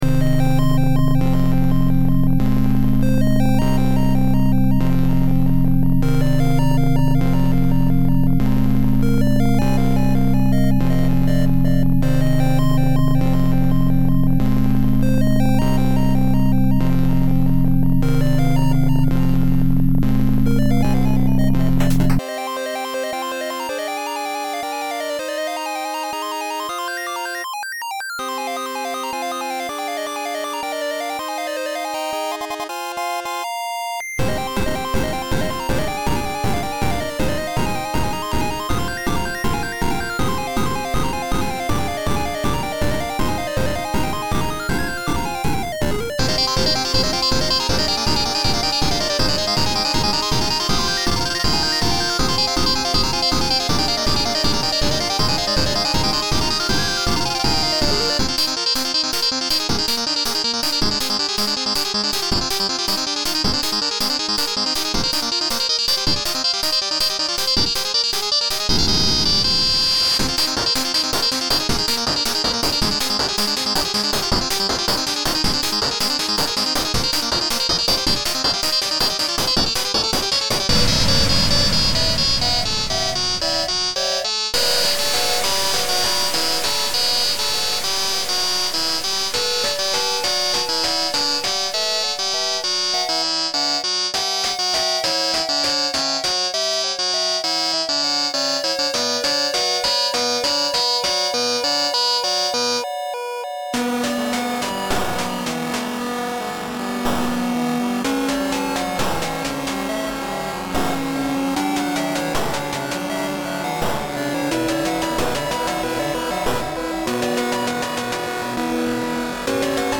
It’s called INSANITY, it is a chiptune song that is about 7:30 long.
The goal of this song was to be about as chaotic as I could with it.